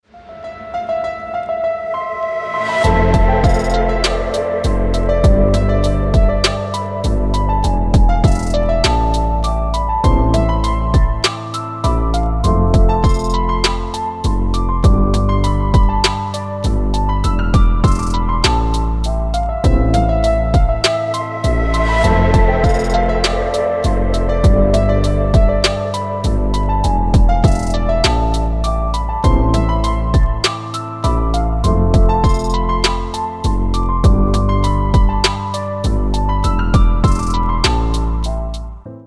• Качество: 320, Stereo
deep house
атмосферные
без слов
клавишные
crossover
пианино
расслабляющие
Прекрасный кроссовер